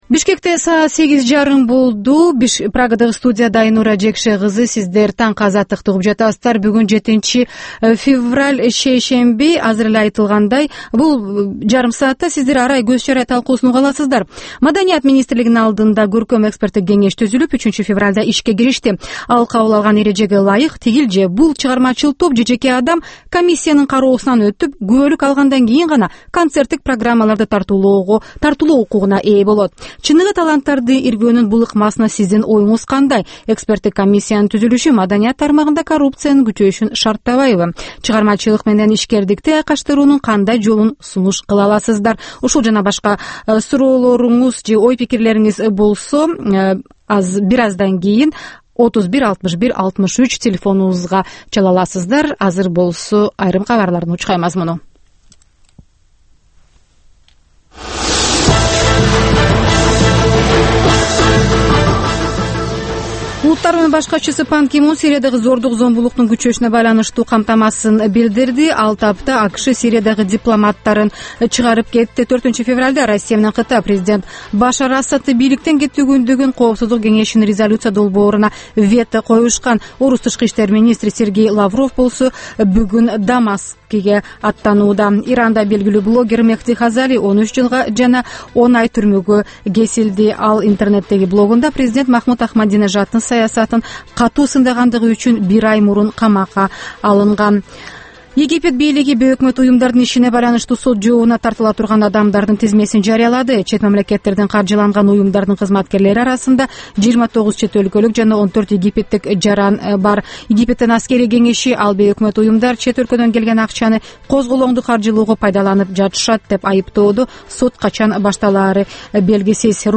Бул таңкы үналгы берүүнүн 30 мүнөттүк кайталоосу жергиликтүү жана эл аралык кабарлар, ар кыл орчун окуялар тууралуу репортаж, маек, күндөлүк басма сөзгө баяндама, «Коом жана турмуш» түрмөгүнүн алкагындагы тегерек үстөл баарлашуусу, талкуу, аналитикалык баян, сереп жана башка берүүлөрдөн турат.